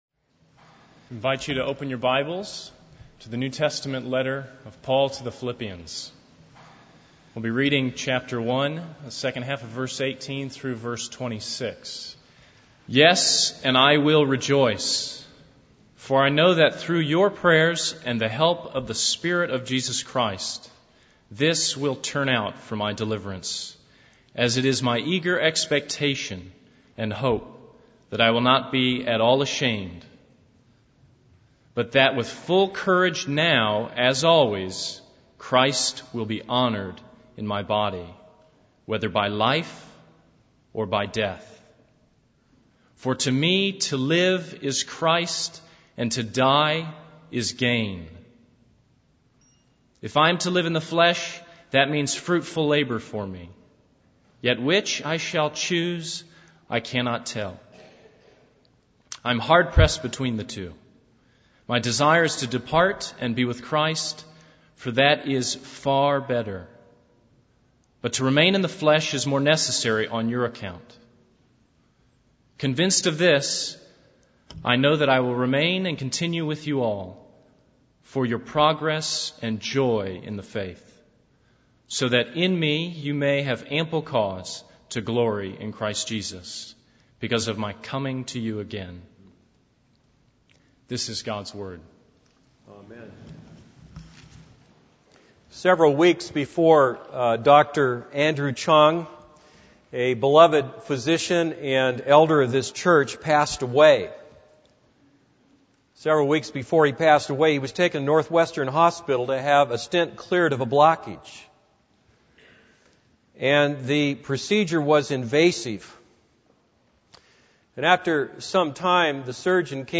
This is a sermon on Philippians 1:18-26.